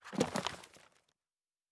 pgs/Assets/Audio/Fantasy Interface Sounds/Book 05.wav at master